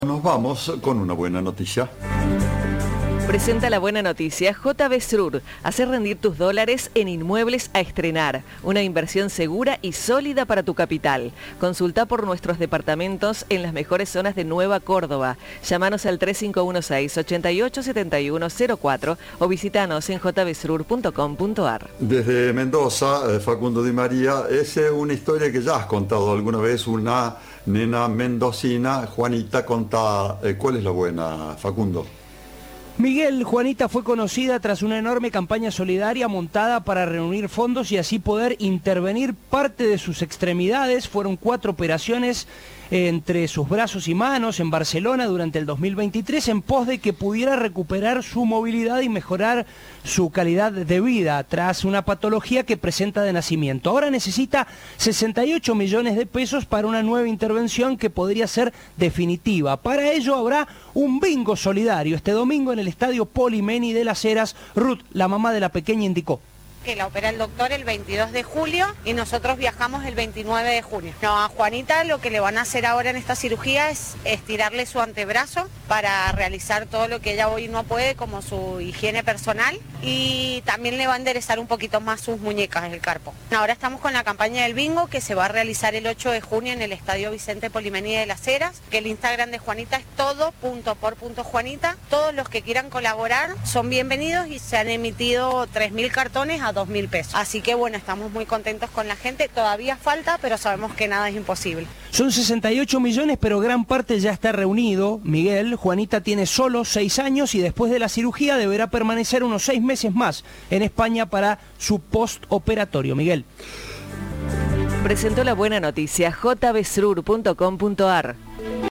Nueva versión de la canción de Diversidad por el coro y orquesta de Juvips
En esta oportunidad fue interpretada por cerca de 40 niños y 20 músicos que pusieron todo su talento para darle vida a una letra que habla sobre la importancia de la convivencia y la empatía.